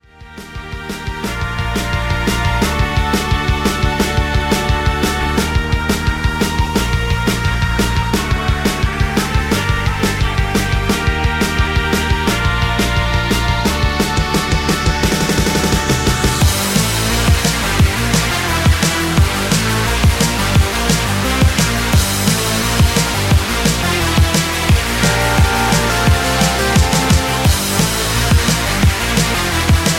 Fm
MPEG 1 Layer 3 (Stereo)
Backing track Karaoke
Pop, Rock, 2010s